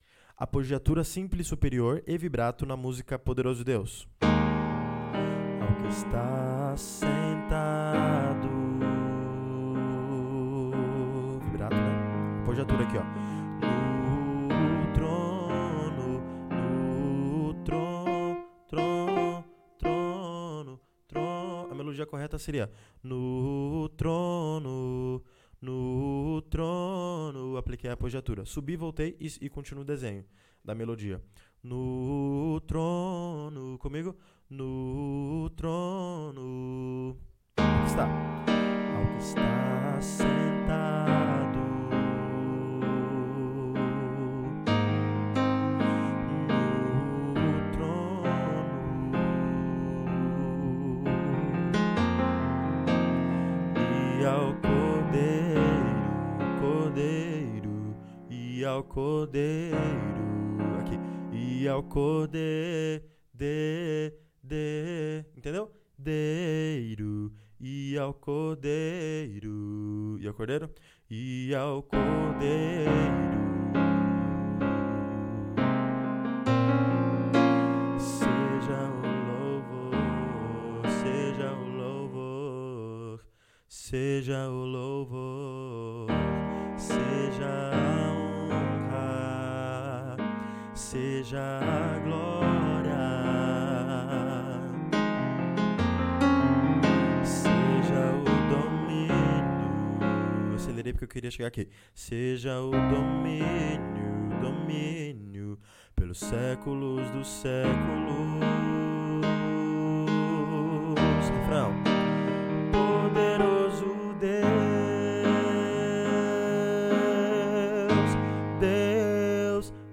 villa / CURSOS DIVERSOS / Maratona Vocal 3.0 - Curso de Canto / 9. Vibrato e Apogiatura / 6. 06_Apogiaturas e Vibrato - Poderoso Deus Outros MP3 1. 011_16_Apogiaturas e Vibrato - Poderoso Deus.mp3 cloud_download